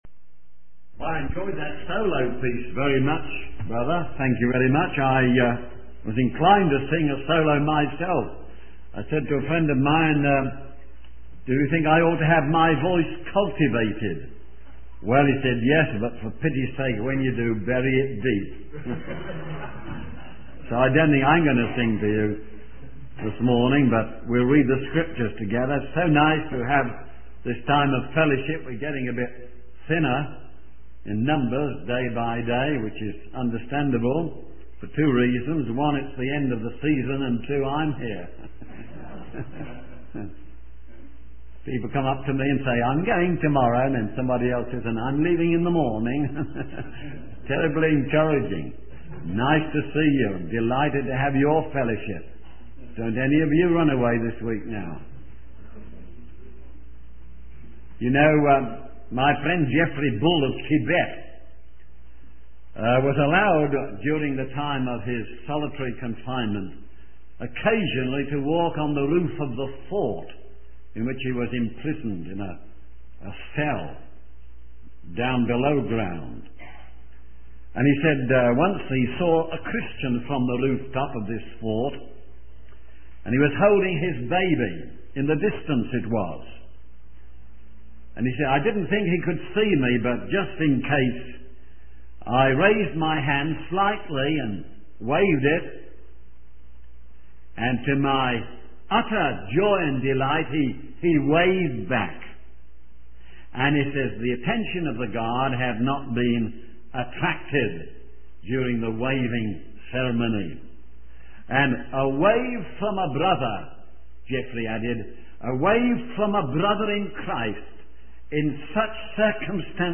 In this sermon, the preacher emphasizes the importance of preaching the Word of God effectively and in the power of the Holy Spirit.